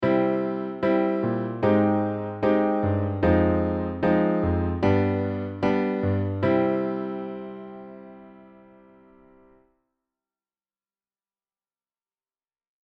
コードネームはⅥ♭、通名「傾国の美女」さんです。
この方の特徴は時にはしなやか妖艶に、時には力強く二面性を持つ事です。
これは、妖艶だよ…女スパイだよ…。